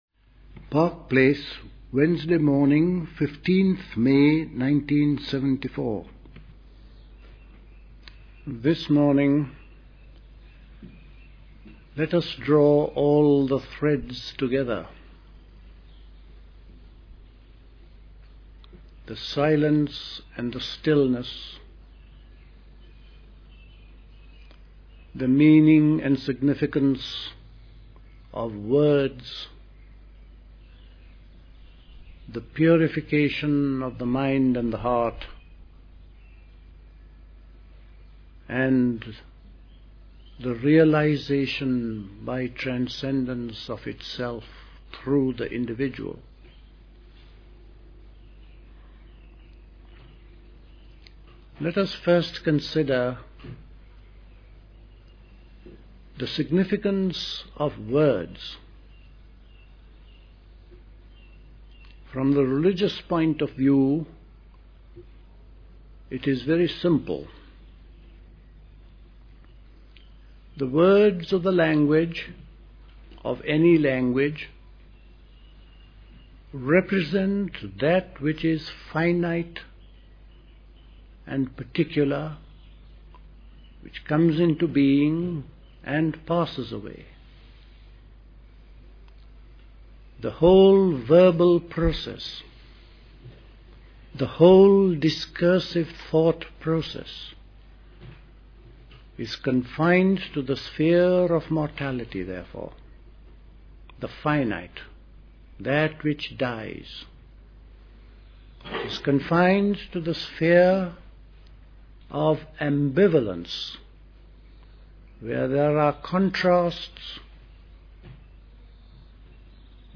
Recorded at the 1974 Park Place Summer School.